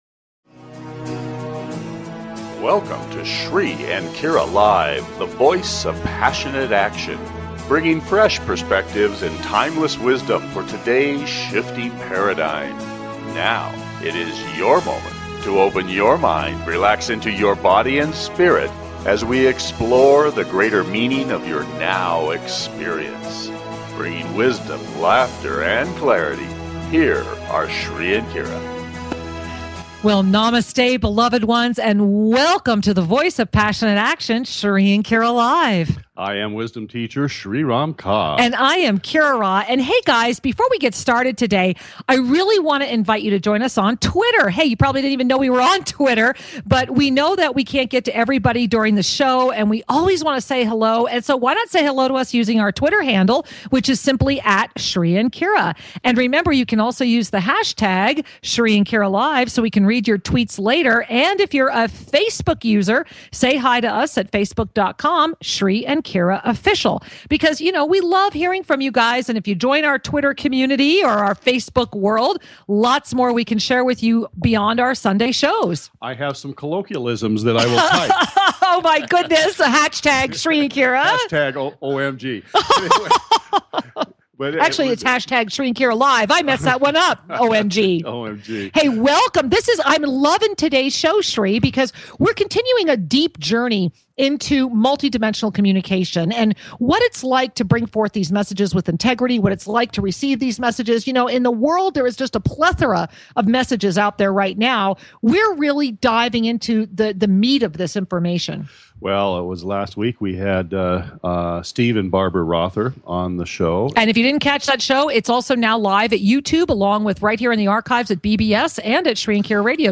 Talk Show Episode
A revelatory show with open phone lines!